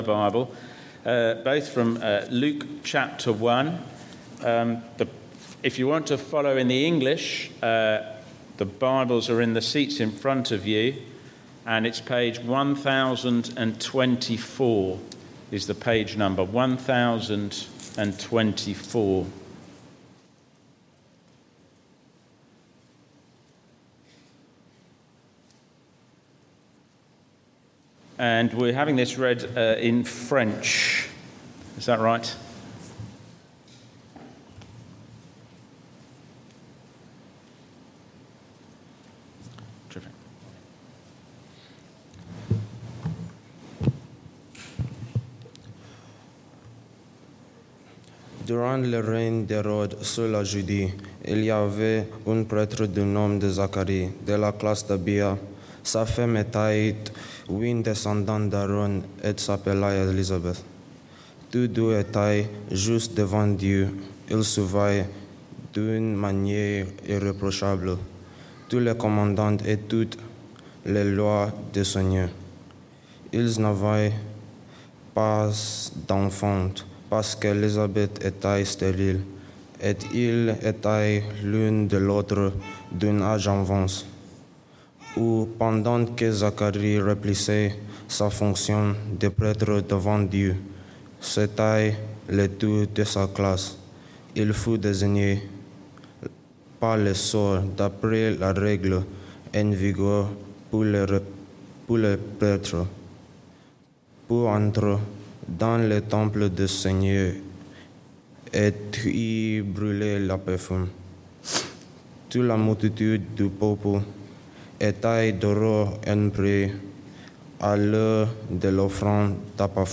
Sermons – Dagenham Parish Church